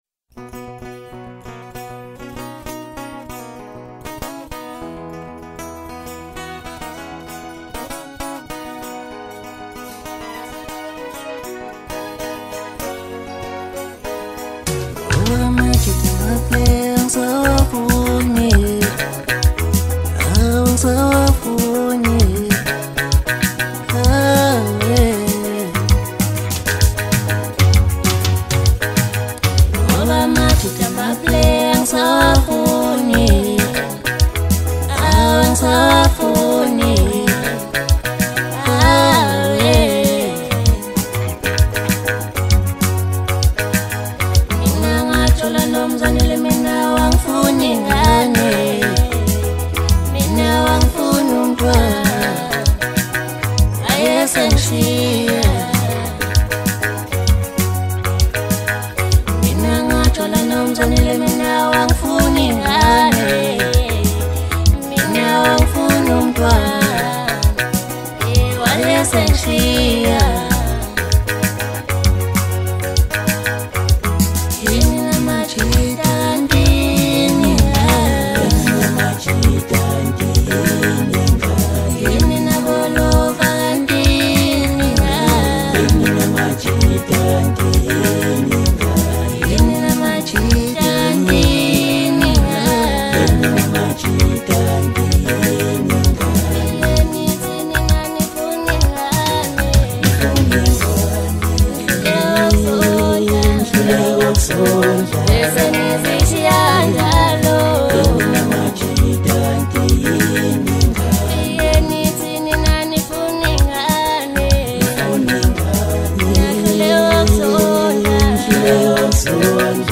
South African singer-songwriter